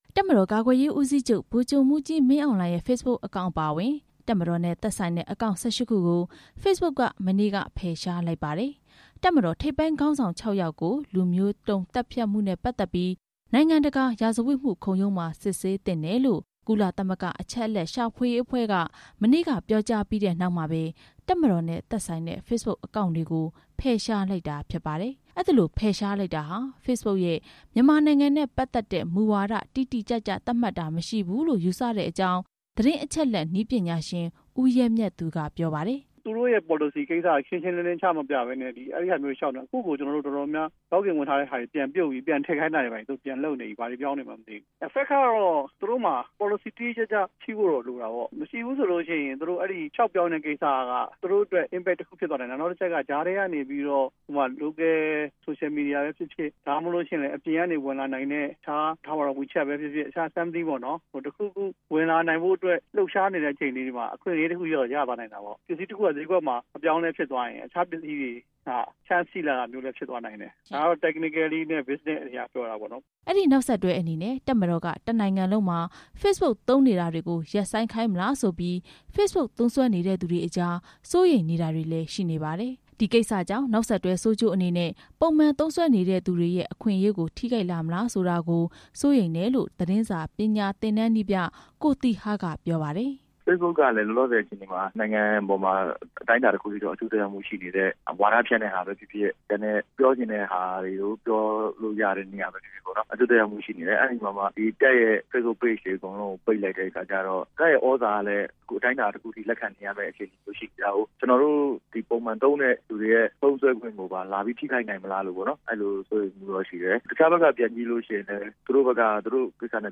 စီးပွားရေးသမားတွေ၊ နည်းပညာကျွမ်းကျင်သူတွေ၊ သတင်းမီဒီယာသမားတွေနဲ့ Facebook သုံးစွဲသူတချို့ရဲ့ ပြောစကားတွေကို